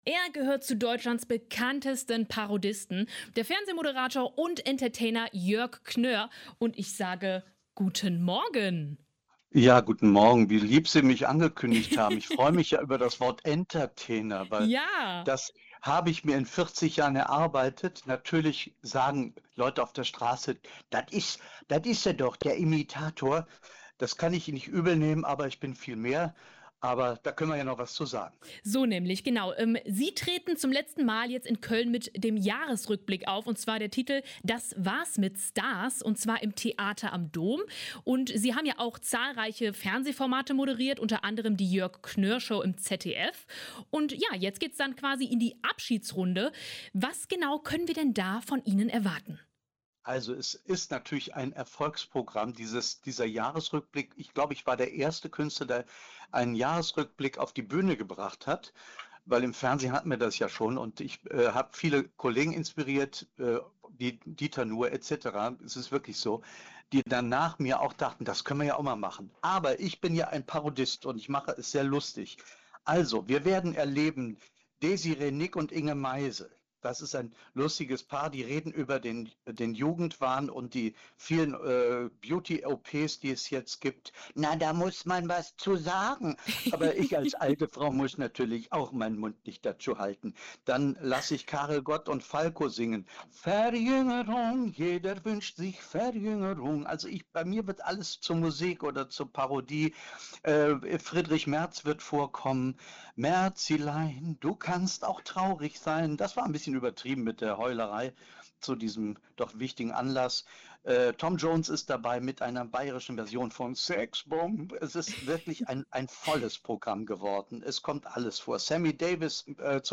Ein Interview mit Jörg Knör (Fernsehmoderator und Entertainer)